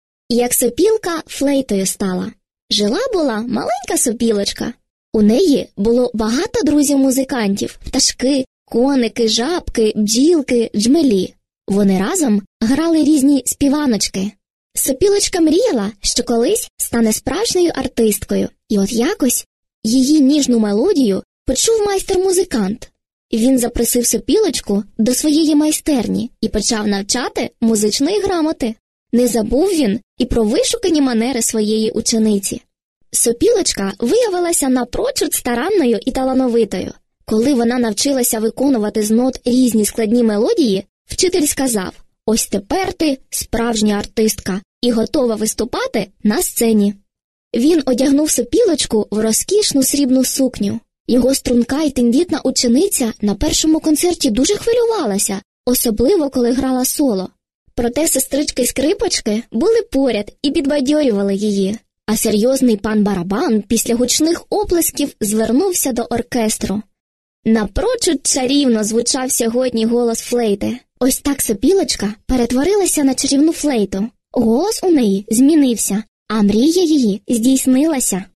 Казка «Як сопілка флейтою стала»